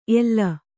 ♪ ella